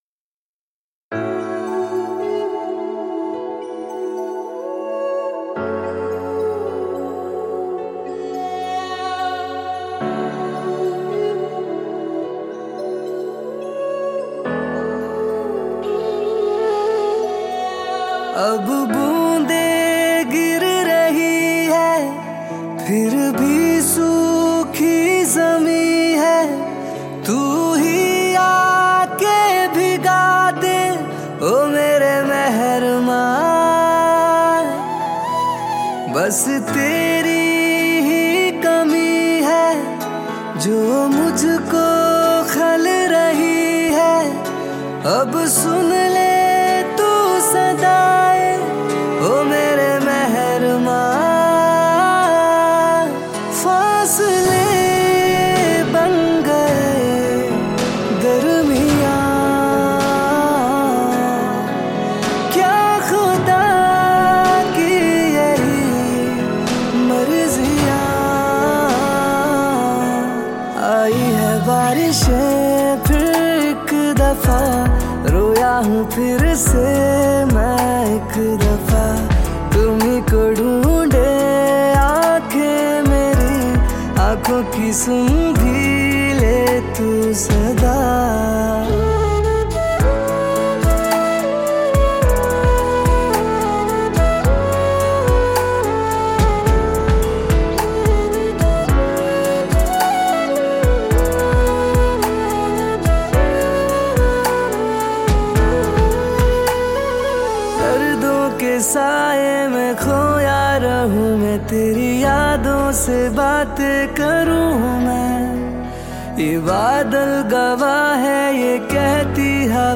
Category New Cover Mp3 Songs 2022 Singer(s